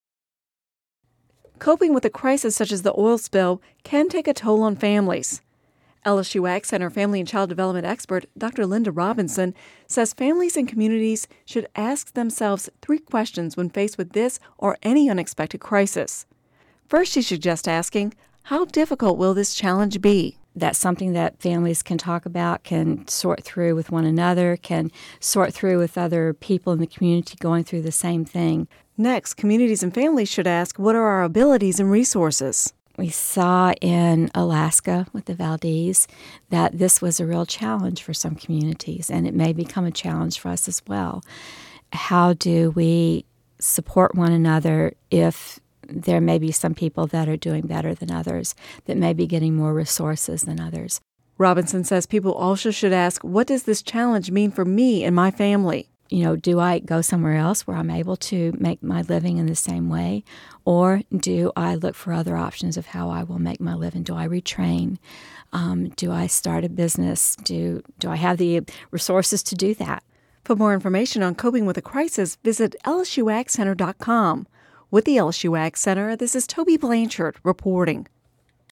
(Radio News 07/12/10) Coping with a crisis can take a toll on families. Many of the people affected by the oil spill were also affected by hurricanes Katrina and Rita.